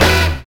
1904L STAB.wav